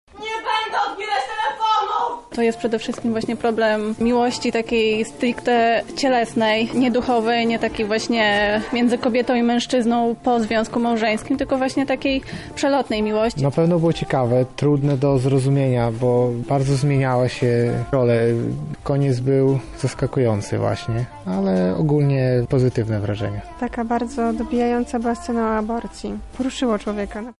„Było wiele świetnie zagranych i poruszających scen” –  mówią widzowie: